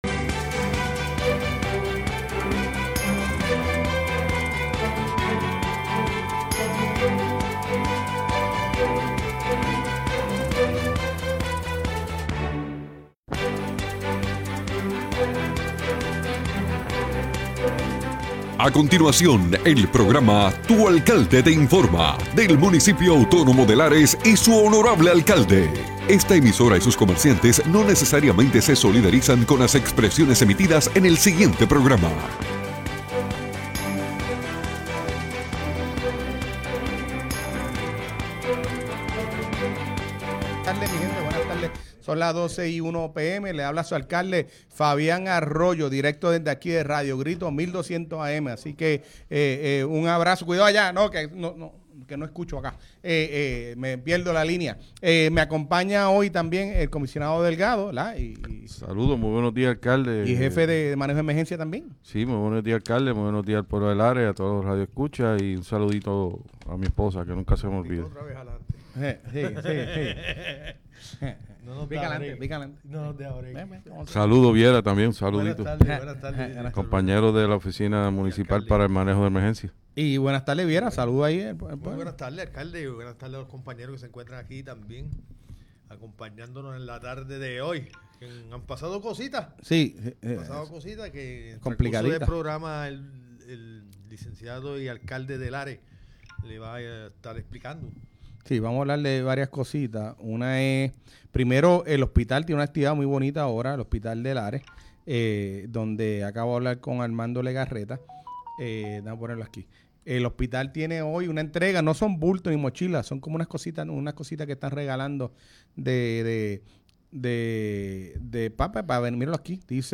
El honorable alcalde de Lares, Fabián Arroyo, junto a su equipo de trabajo nos informan sobre todo las novedades del municipio.